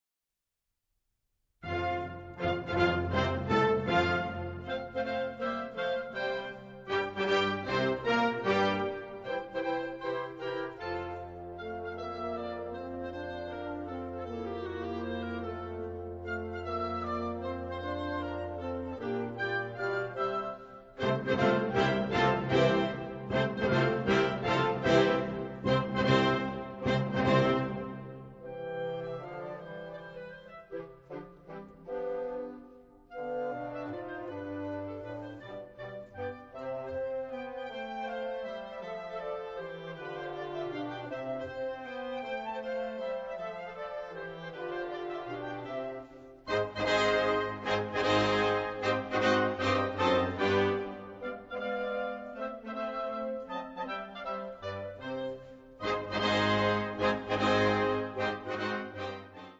Catégorie Harmonie/Fanfare/Brass-band
Sous-catégorie Musique de concert, arrangement
Instrumentation Ha (orchestre d'harmonie)